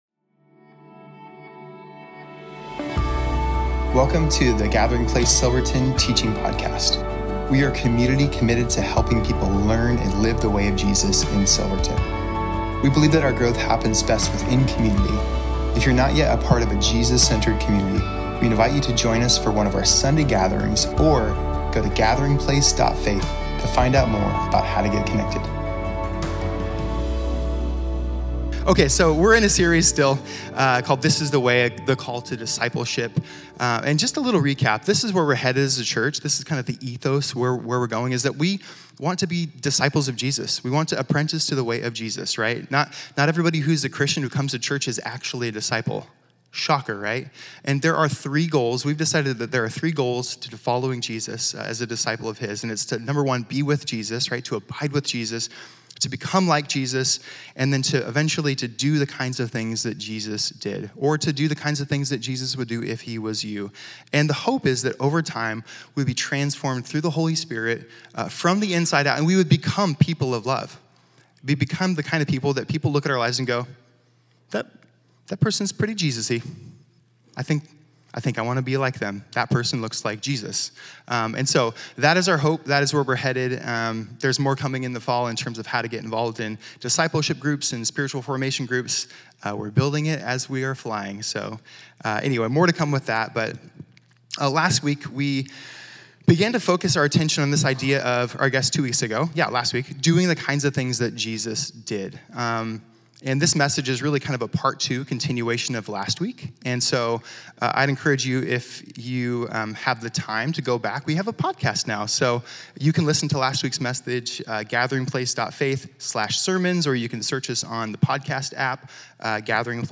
Home About Connect Events Sermons Give This is The Way - The God Who Sees July 2, 2025